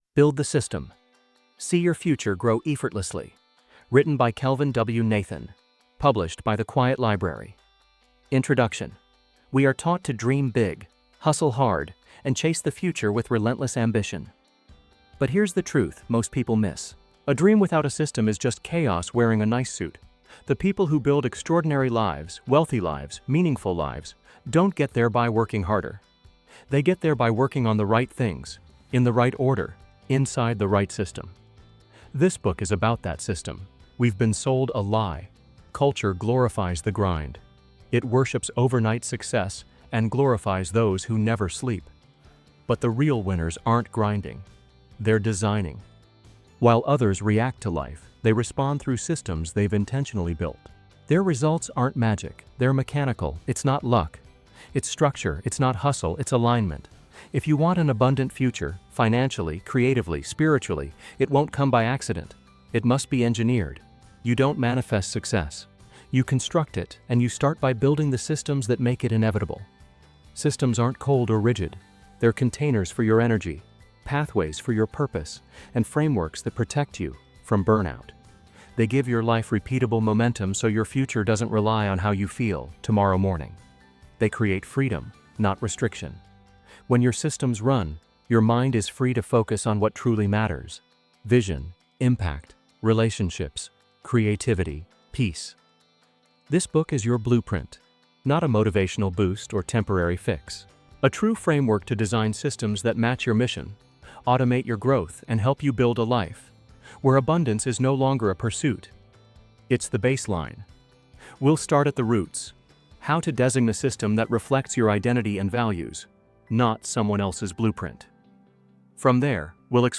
Watch, read, and listen to the audiobook in this micro blog post and start learning how to build systems in your life.